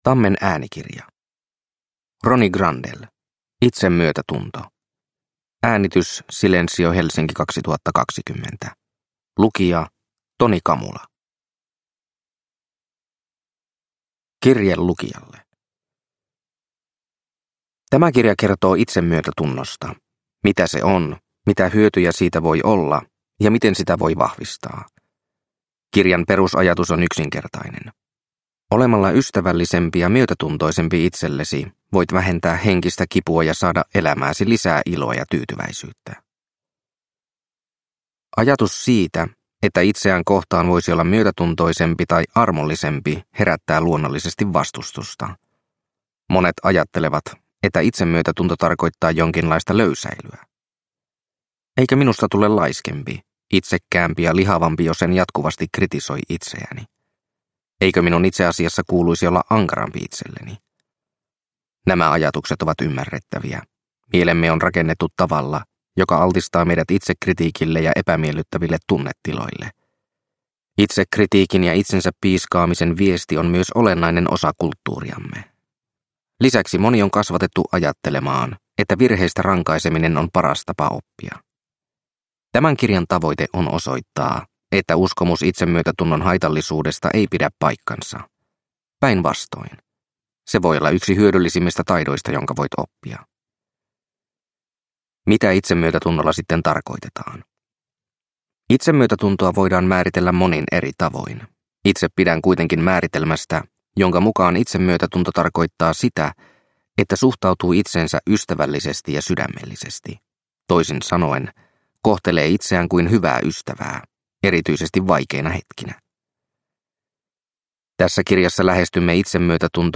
Itsemyötätunto – Ljudbok – Laddas ner